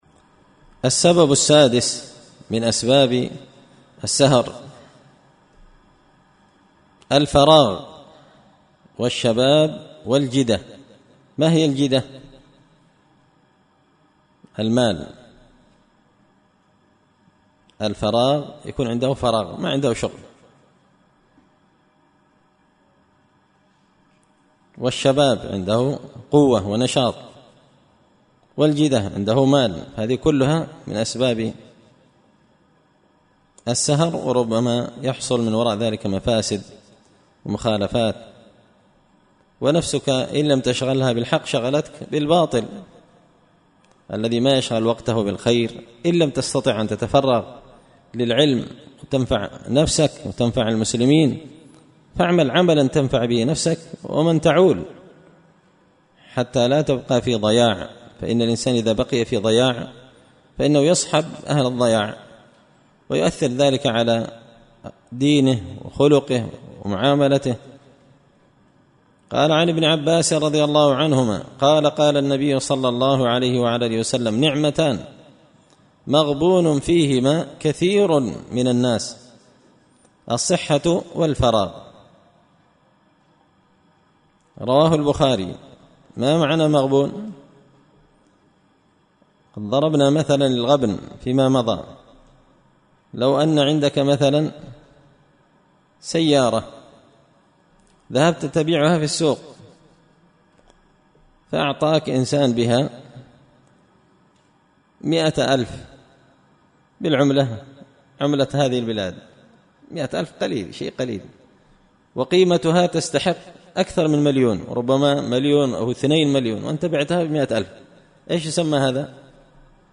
إمتاع النظر بأحكام السمر والسهر ـ الدرس الخامس والعشرون